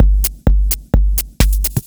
Electrohouse Loop 128 BPM (18).wav